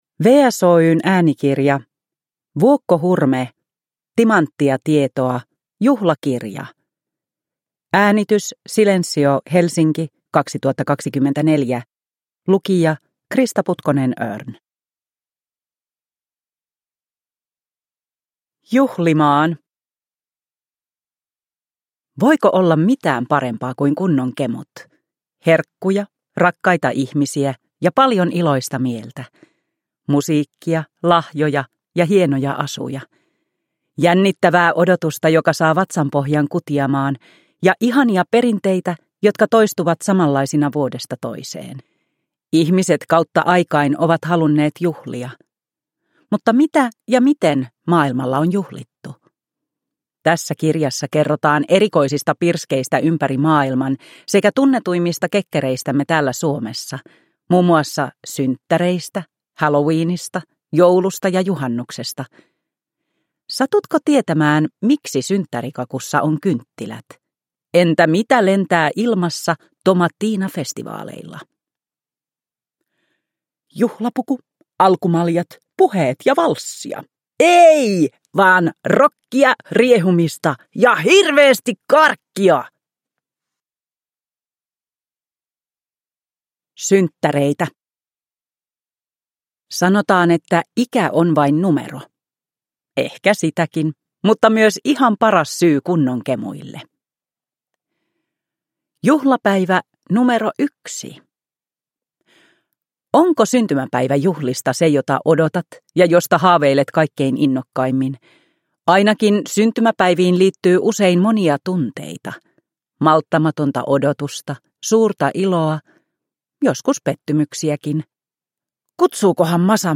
Timanttia tietoa: Juhlakirja – Ljudbok